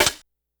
Snares
snr_71.wav